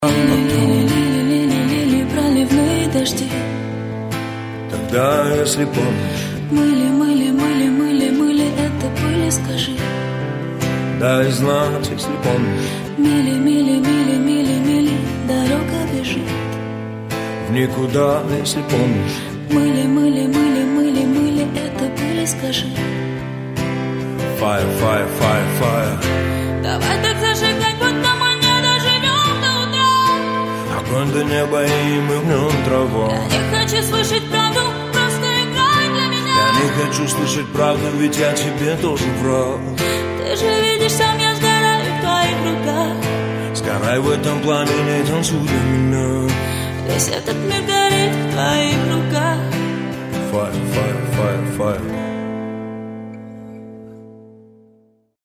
Live, acoustic